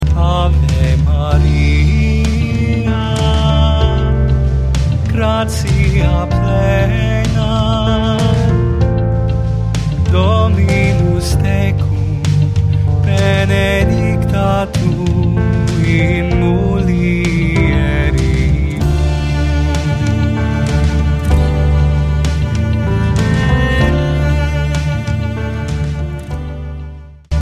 classic and contemporary prayer and praise music